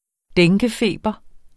Udtale [ ˈdεŋgεˌfeˀbʌ ]